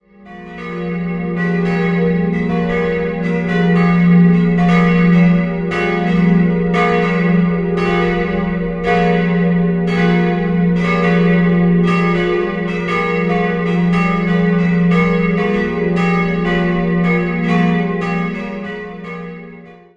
Sie reicht bis in romanische Zeit zurück, der gotische Chor wurde Mitte des 15. Jahrhunderts errichtet. 3-stimmiges Gloria-Geläute: fis'-gis'-h' Alle Glocken wurden von Rincker gegossen.